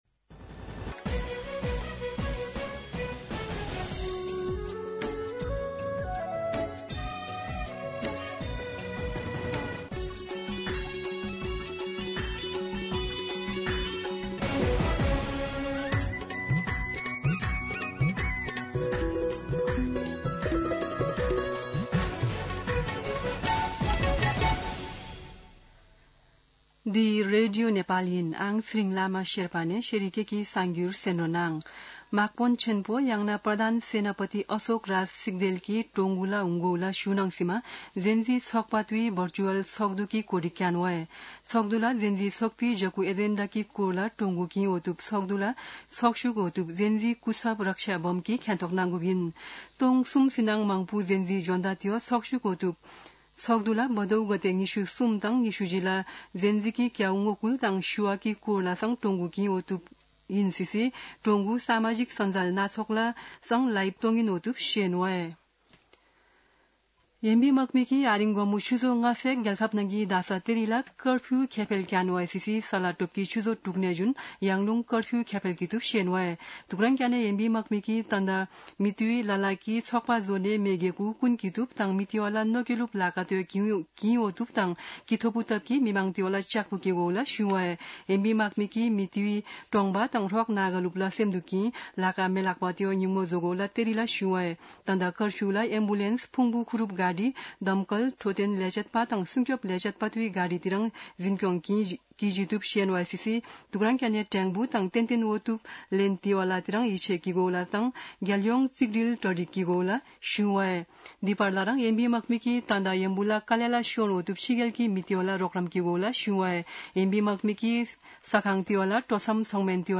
शेर्पा भाषाको समाचार : २५ भदौ , २०८२
Sherpa-News-05-25.mp3